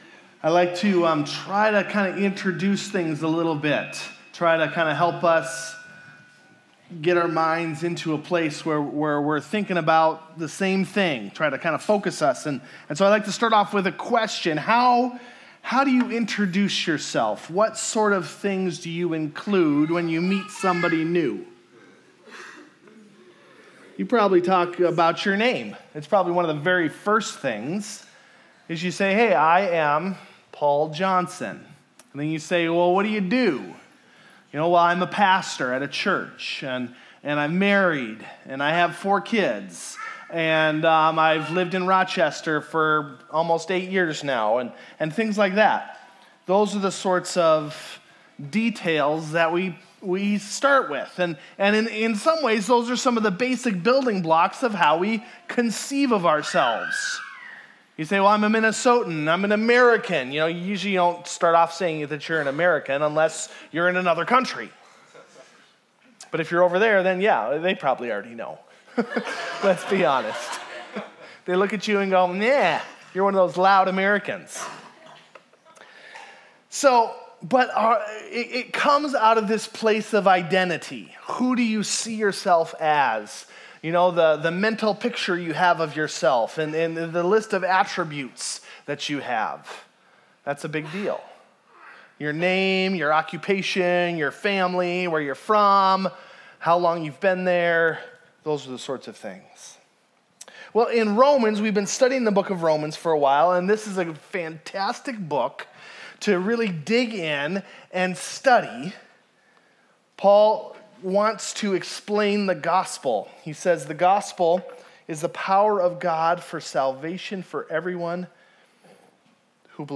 Video Audio Download Audio Home Resources Sermons Identity Mindset Sep 14 Identity Mindset God promises that we can have the victorious life that is promised in the book of Romans, if we get our minds right.